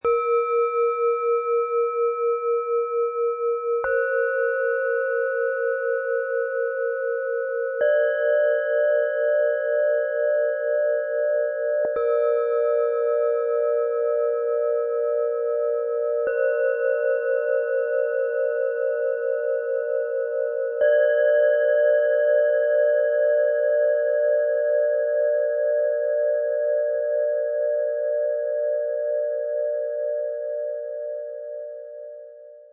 Set aus 3 fein und klar schwingenden Planetenschalen für Klangmeditation & Achtsamkeit
Im Sound-Player - Jetzt reinhören können Sie den Originalton dieser besonderen Schalen live erleben - direkt so, wie sie im Set erklingen.
Der mitgelieferte Klöppel bringt die zarten Frequenzen angenehm zur Geltung.
Diese Klangschalen entfalten gemeinsam ihr feines Klangfeld: Tiefster Ton: Mond
Mittlerer Ton: DNA
Höchster Ton: Saturn